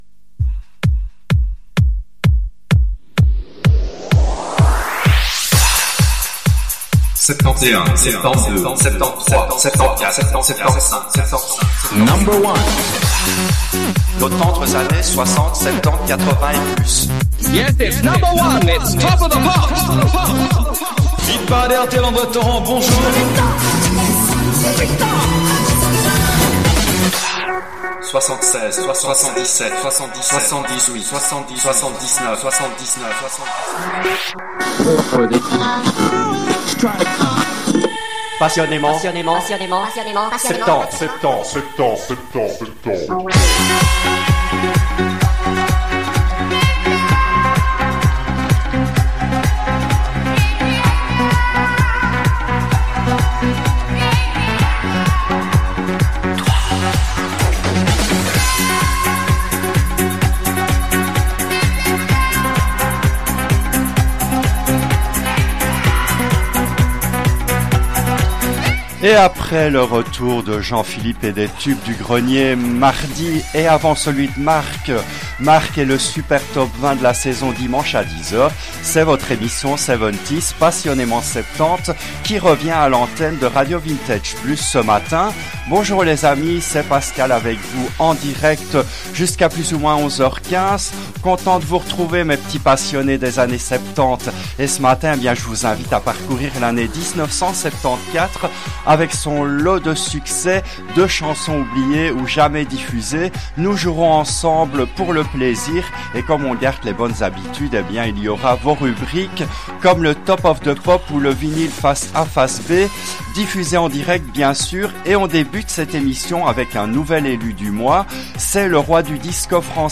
L’émission a été diffusée en direct le jeudi 12 juin 2025 à 10h depuis les studios belges de RADIO RV+